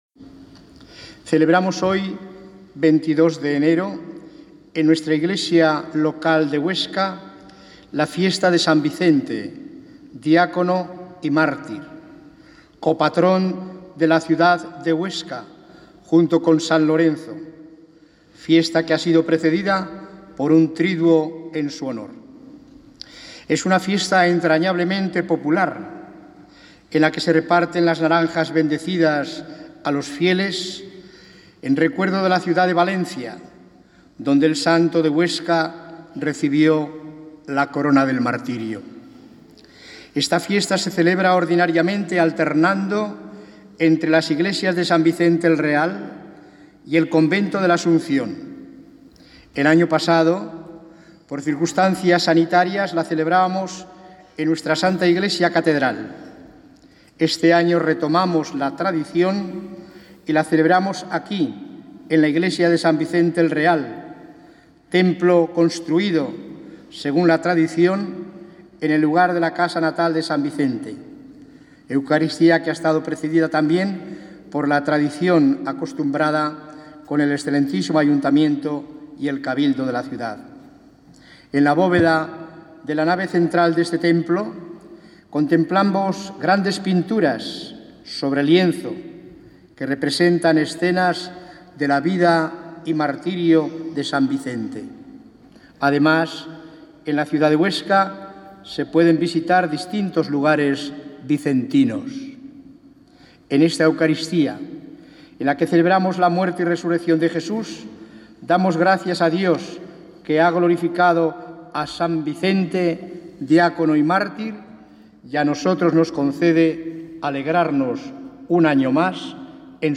La diócesis de Huesca ha celebrado este miércoles 22 de enero la fiesta de san Vicente Mártir, copatrón de la ciudad, con una misa estacional que ha tenido lugar en la iglesia de san Vicente Mártir y ha estado presidida por nuestro administrador apostólico, monseñor Vicente Jiménez Zamora.
HOMILÍA DE MONSEÑOR VICENTE JIMÉNEZ ZAMORA (TEXTO)
Homilia-San-Vicente-22-1-25.mp3